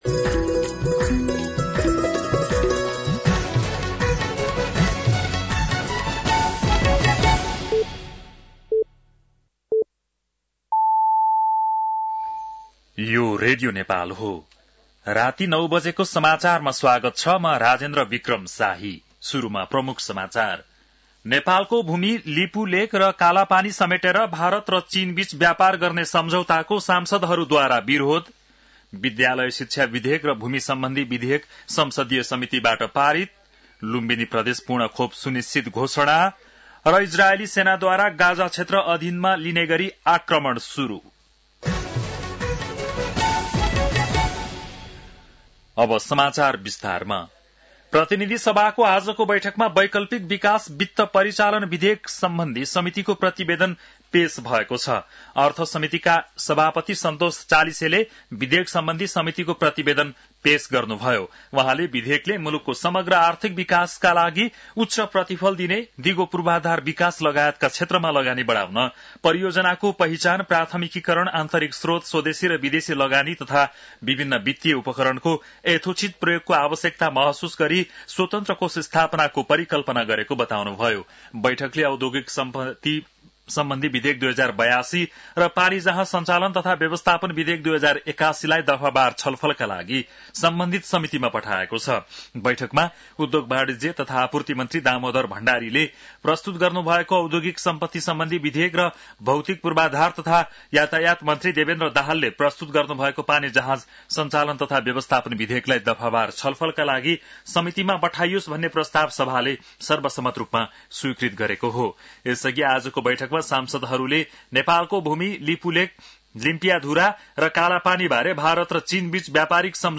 बेलुकी ९ बजेको नेपाली समाचार : ५ भदौ , २०८२
9-PM-Nepali-NEWS-05-05.mp3